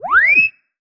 sci-fi_driod_robot_emote_08.wav